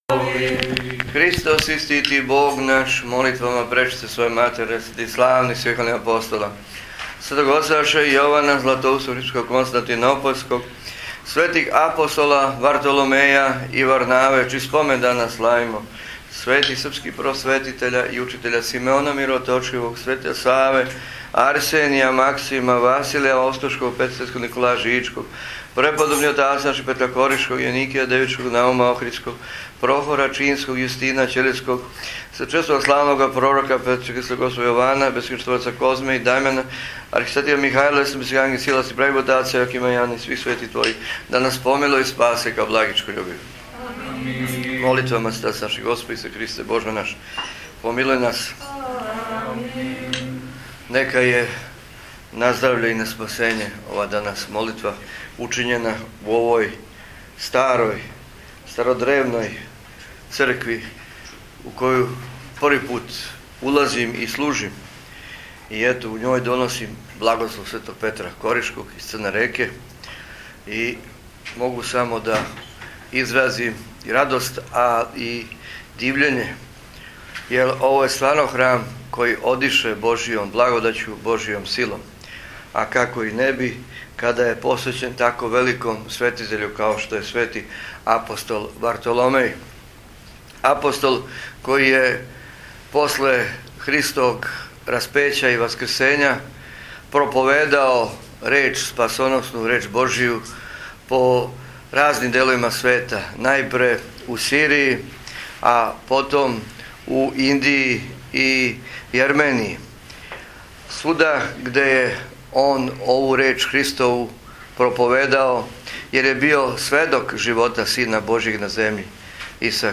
Прослава Св Ап Вартоломеја 2014.